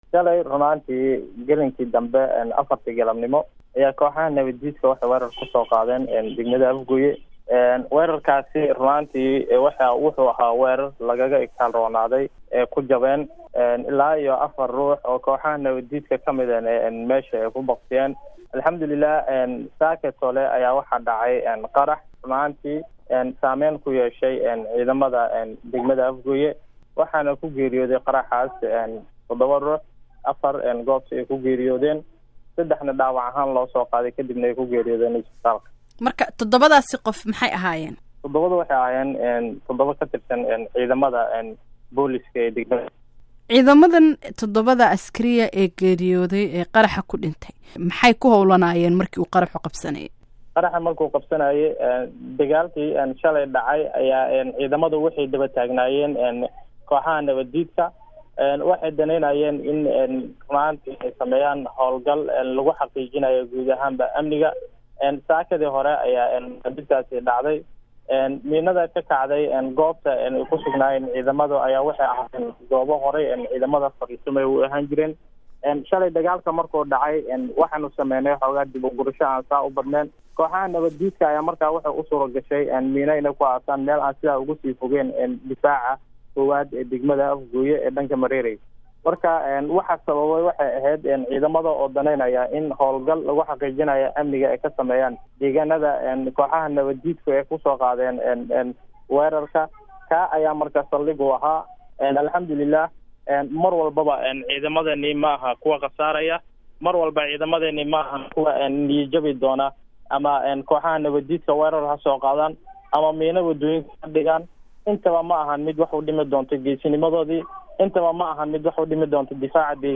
waxay ka waraysatay guddoomiyaha degmada Afgooye Cabdinaasir Caalim Macalim Ibraahim.